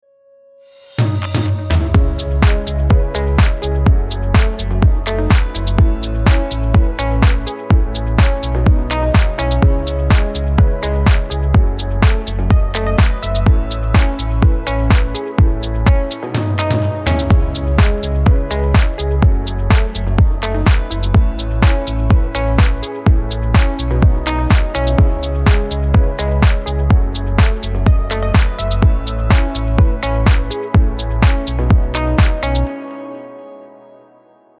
Звук в результате баунсинга становиться явно хуже.